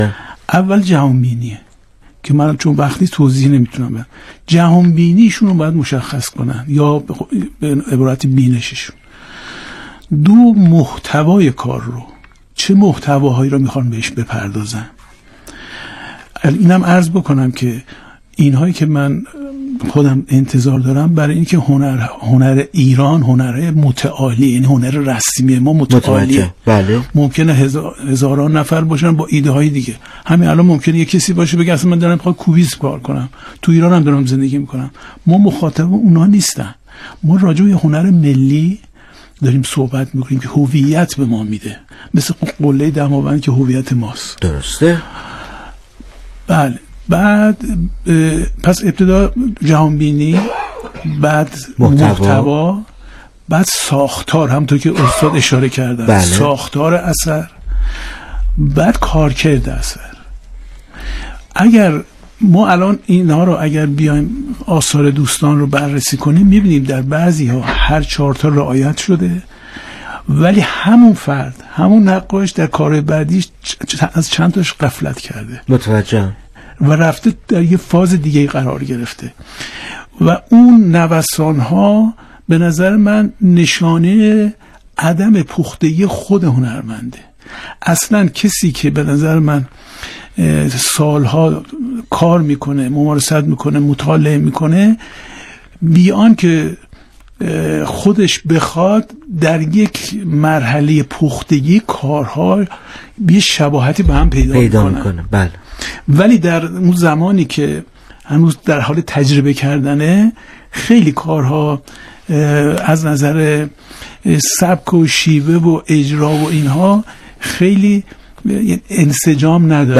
میزگرد آسیب‌شناسی هنر نقاشی انقلاب اسلامی/ 2